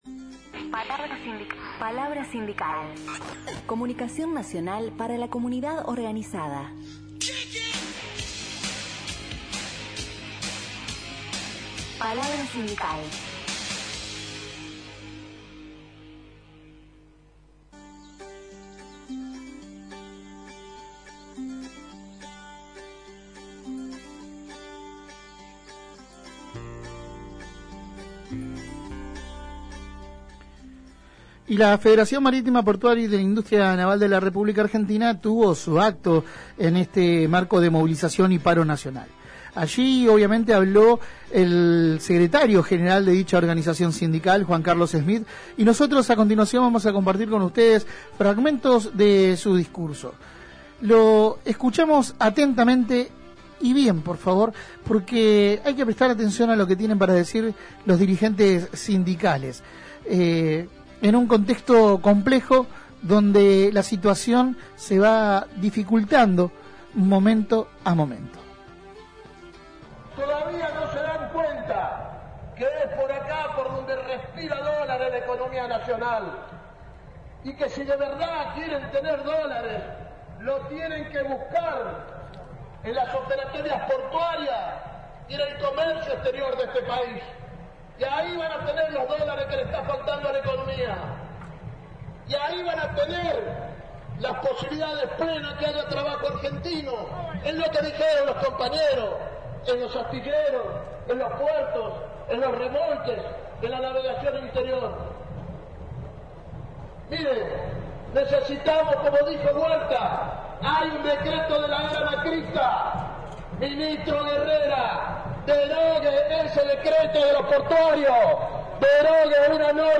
Compartimos parte del discurso: